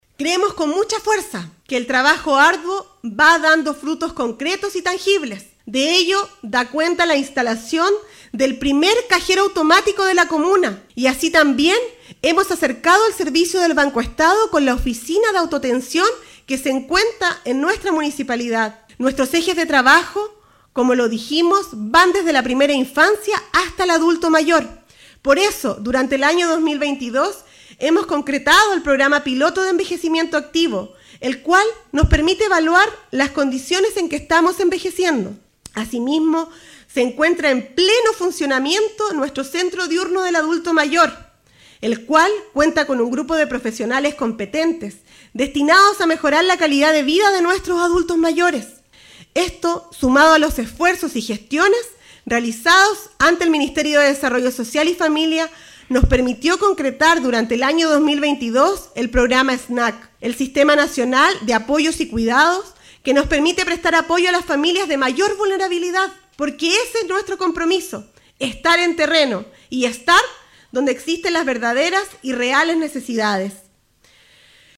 La ceremonia se desarrolló en dependencias del Centro Cultural, en el marco de una sesión extraordinaria del Concejo Municipal, en cumplimiento a la Ley Orgánica Constitucional de Municipalidades, donde la autoridad dio cuenta de la amplia cartera de proyectos gestionados y ejecutados durante su segundo año de mandato comunal y que favorecen en el desarrollo de los curacanos, con avances significativos en ejes como infraestructura pública y comunitaria; deportiva, cultural y de turismo, medioambiente, de salud y educativa, de servicios básicos, entre otras.